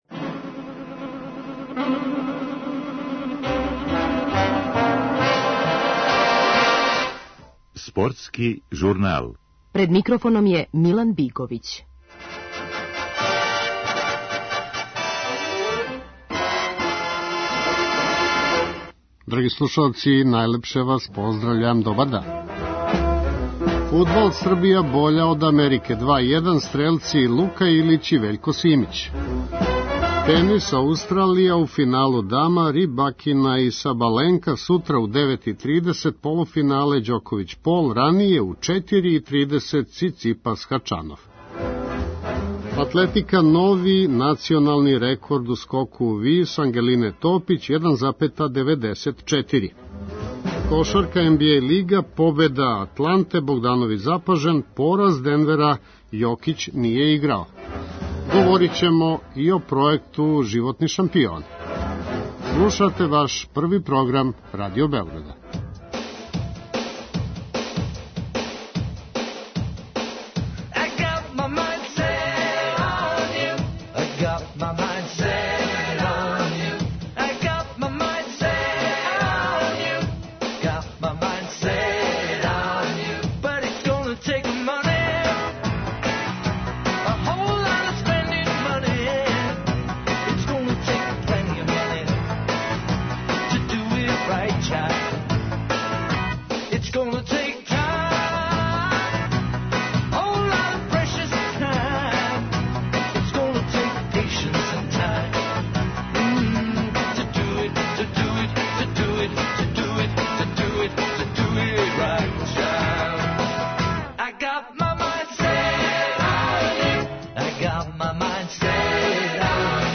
Анализирамо форму Новака Ђоковића, кога сутра од 9,30 у Мелбурну очекује полуфинале Грен-слем турнира, на којем очекујемо нови трофеј и повратак на прво место светске ранг листе. Нас саговорник је тениски тренер